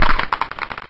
die_skeleton.ogg